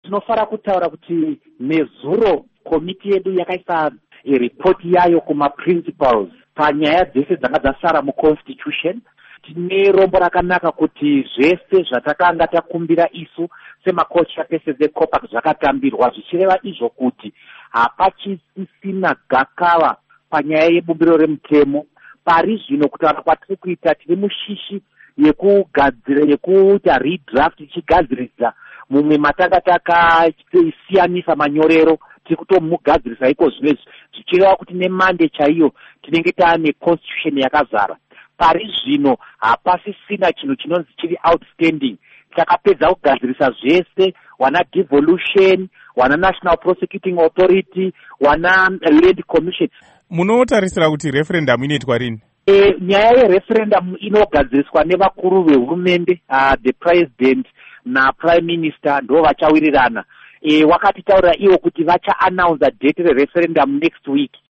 Hurukuro naVaAmai Priscilla Misihairambwe Mushonga